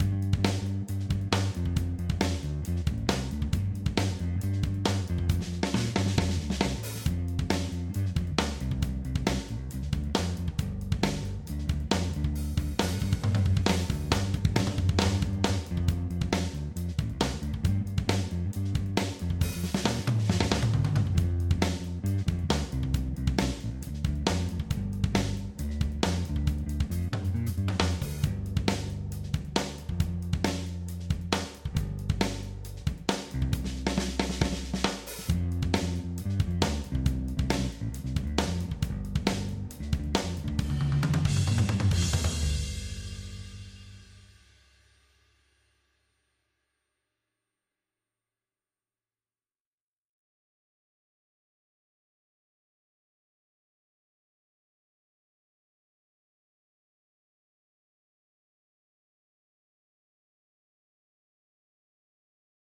studio test - just drum and bass